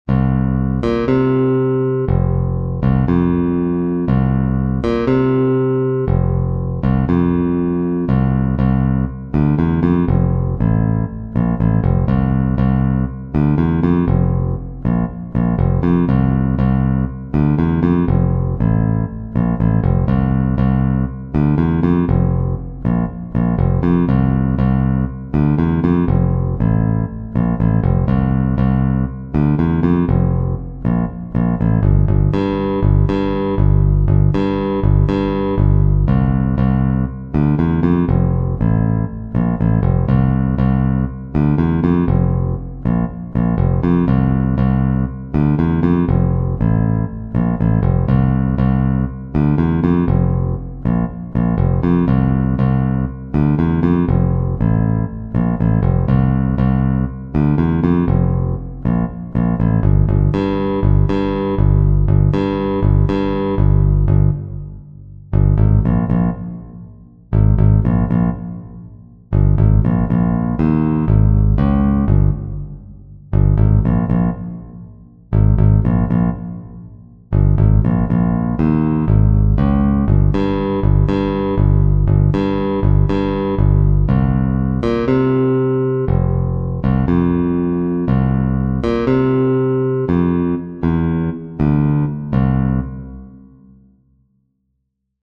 January_1st_Parts-Bass.mp3